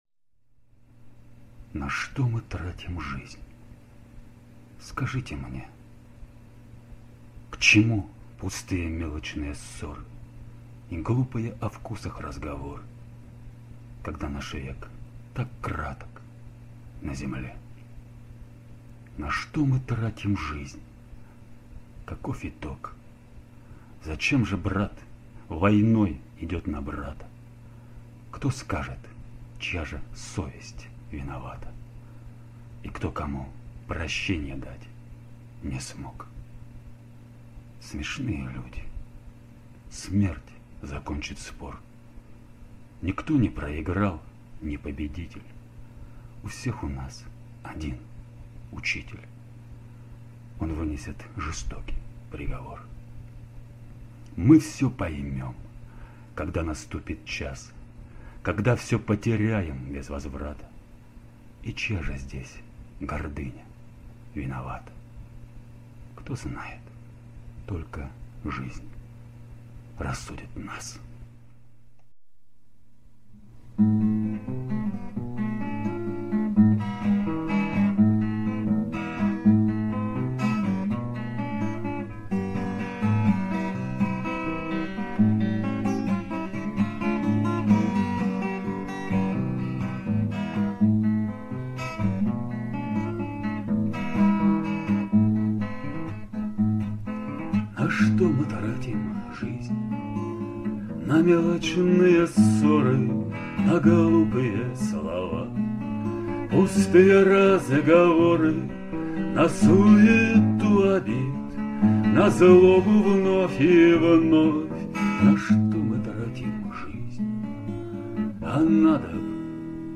Запись с турслёта (2010 год).
Объединил стихи и песню в один файл.
Новый вариант (нормальное звучание):